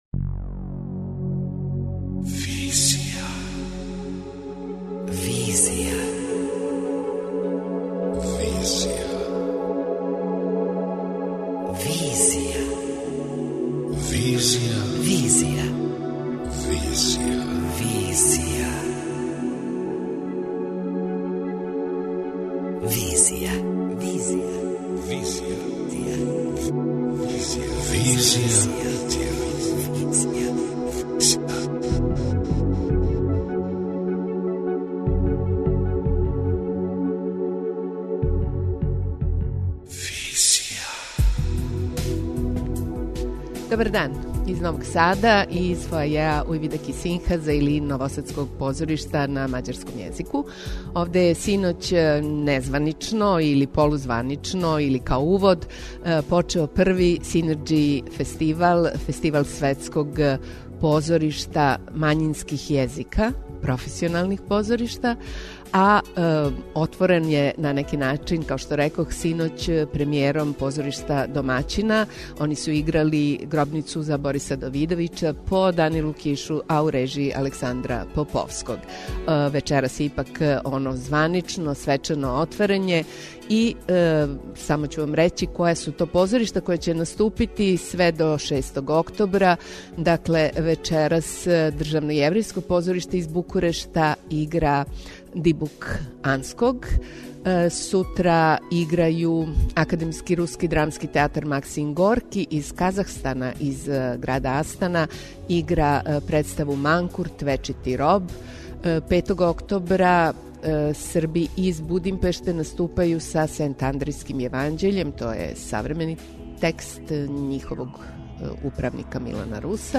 Двестадвојка у Новом Саду, уживо
Тим поводом, данас емитујемо Специјал емисију Арс и Визија, од 17 до 19 сати, из фоајеа Ујвидеки синхаза.